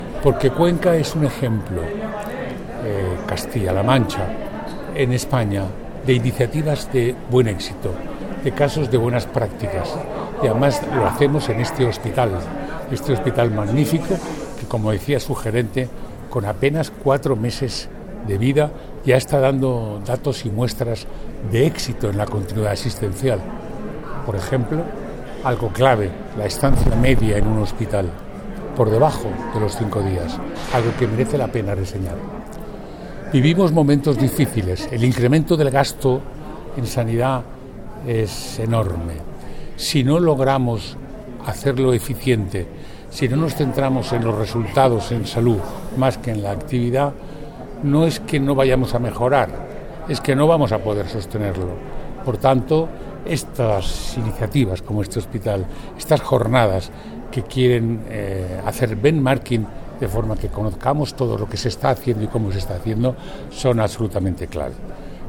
Celebrada la Jornada «El abordaje de la continuidad asistencial en CLM» en el Hospital Universitario de Cuenca: avances para un modelo asistencial centrado en las necesidades del paciente
Intervención